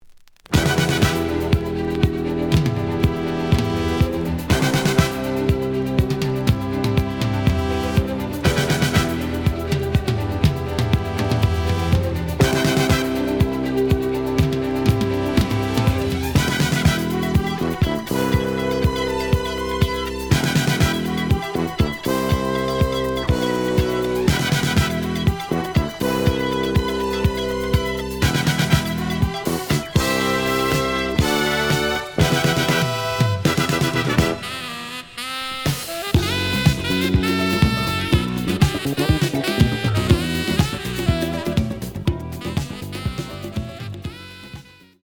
(Stereo)
試聴は実際のレコードから録音しています。
●Genre: Jazz Funk / Soul Jazz
●Record Grading: EX- (プロモ盤。)